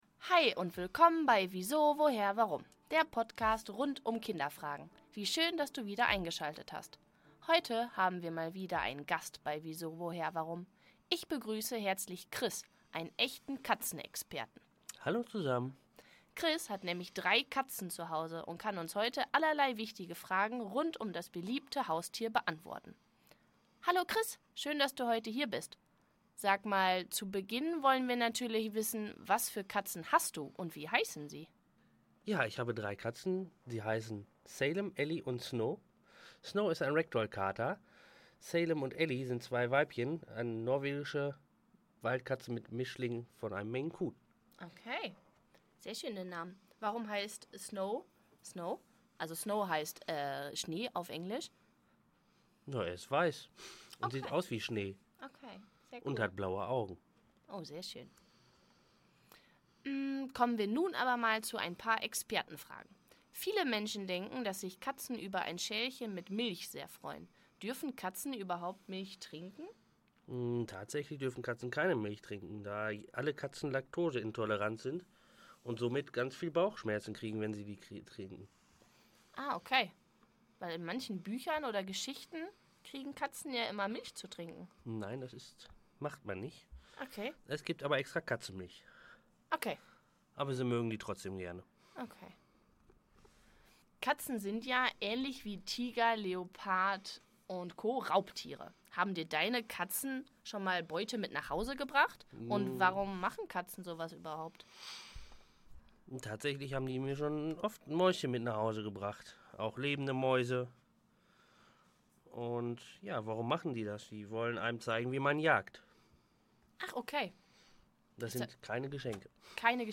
Katzenexperte zu Gast